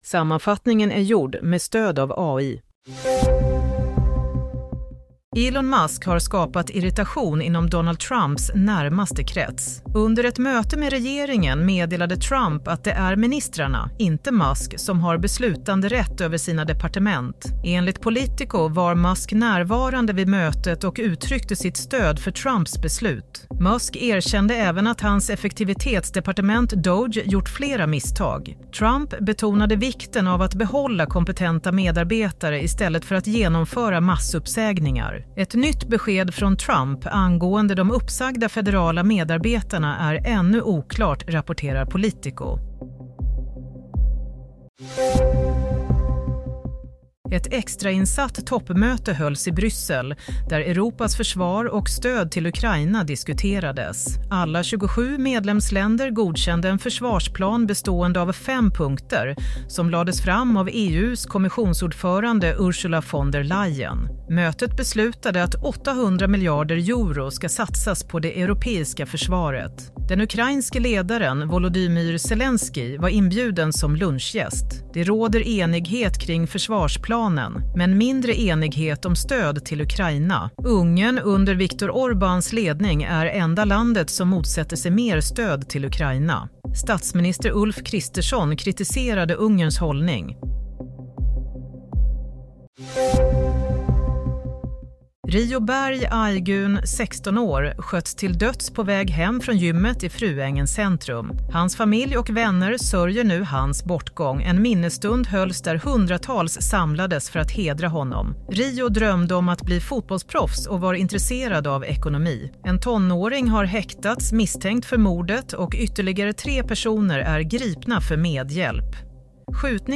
Nyhetssammanfattning - 7 mars 07:00